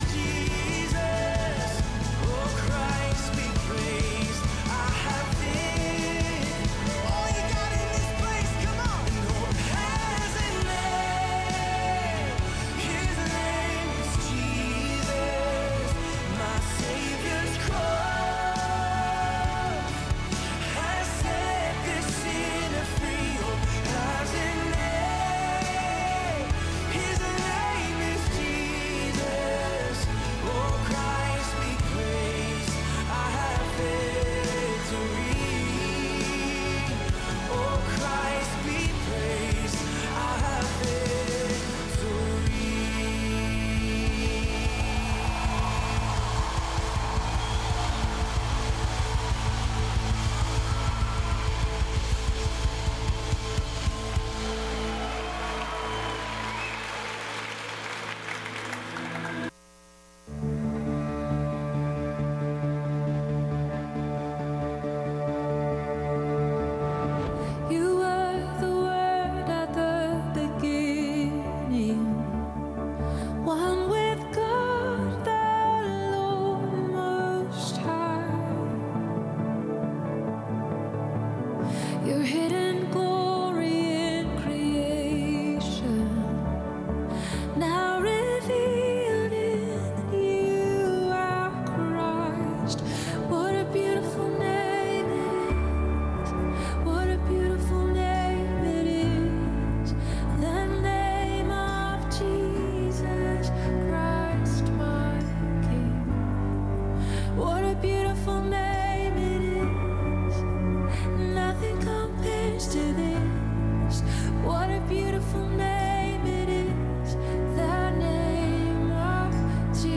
Calvary Knoxville Sunday Night of Prayer